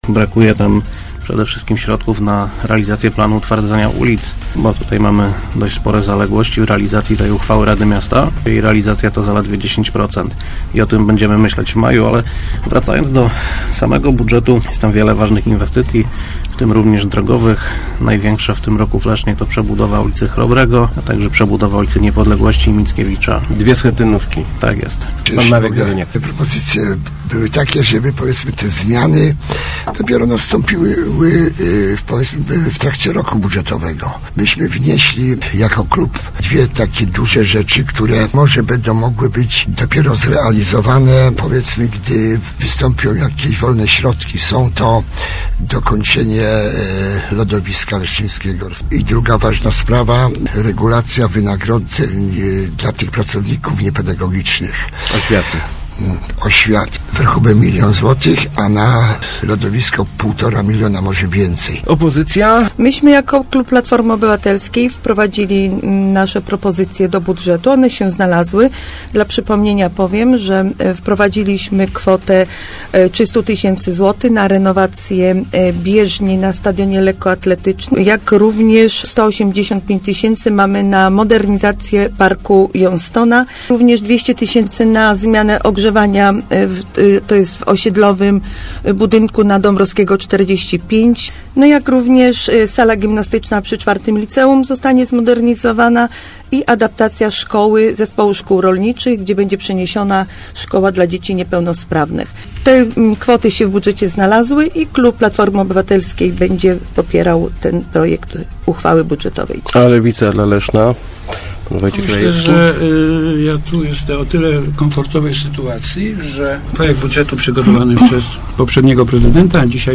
Przygotowany przez poprzedniego prezydenta Leszna projekt budżetu na 2015 rok otrzyma najprawdopodobniej poparcie wszystkich radnych. - Ewentualne zmiany mogą zostać wprowadzone w ciągu roku budżetowego – mówili w radiu Elka przedstawiciele klubów Rady. PL 18 chciałby przeznaczyć więcej pieniędzy na utwardzanie ulic, PiS na dokończenie lodowiska i podwyżki dla niepedagogicznych pracowników oświaty.